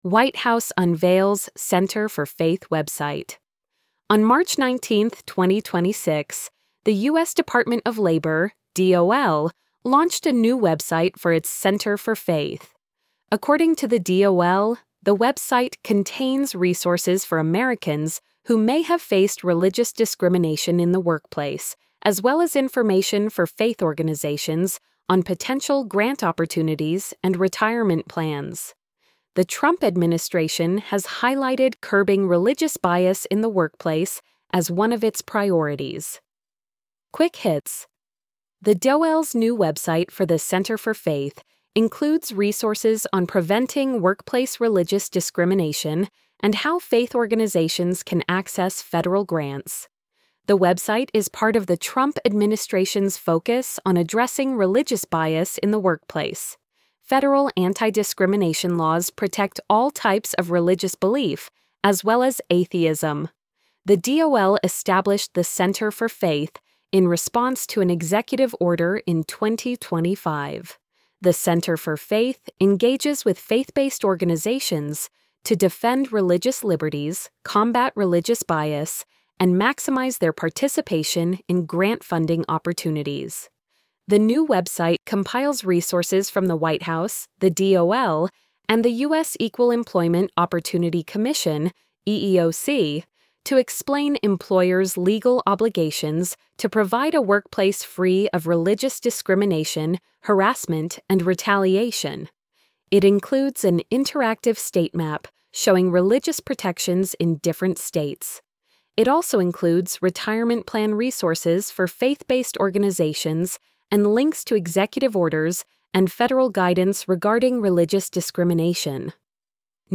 white-house-unveils-center-for-faith-website-tts.mp3